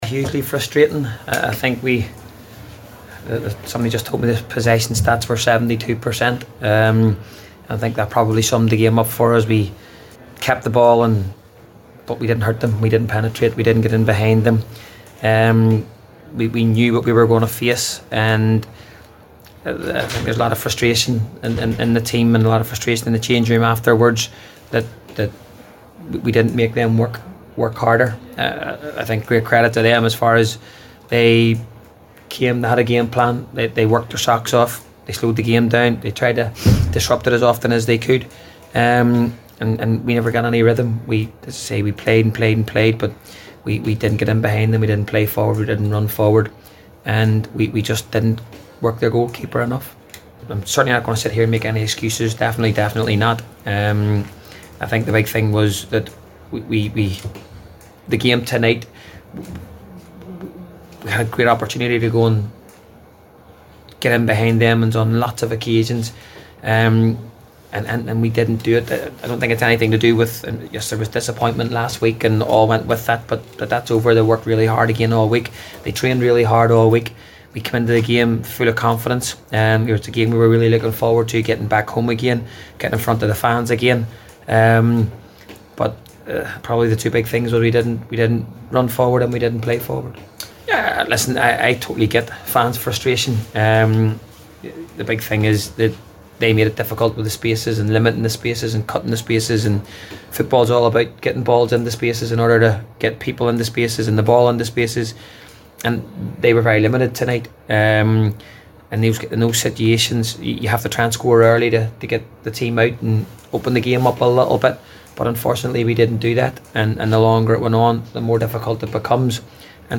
He spoke to the assembled media after the match…